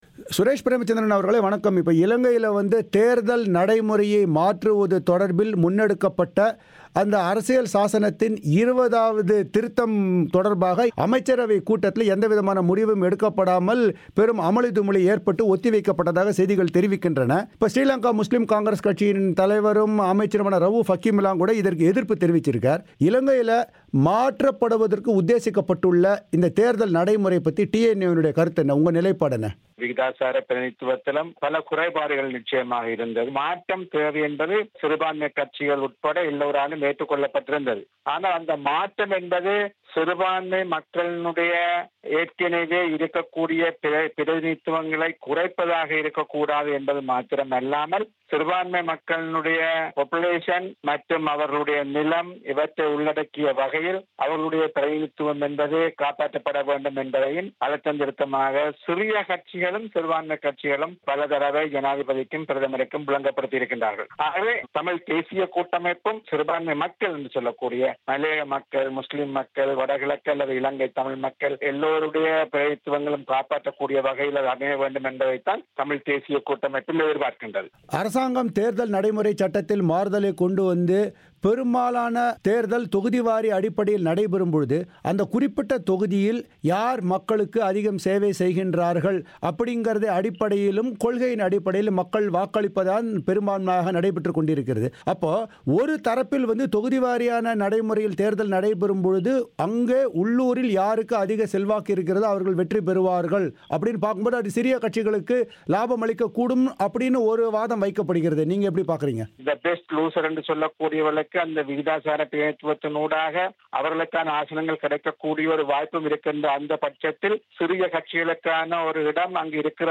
இலங்கையில் தேர்தல் நடைமுறைகளை மாற்ற வழி செய்யும் அரசியல் சாசனத்தில் இருபதாம் திருத்தம் குறித்து தமிழ்த் தேசியக் கூட்டமைப்பின் நிலைப்பாடு குறித்து, அதன் நாடாளுமன்ற உறுப்பினரும் பேச்சாளருமான சுரேஷ் பிரேமச்சந்ந்திரனின் பிபிசி தமிழோசையிடம் தெரிவித்தவை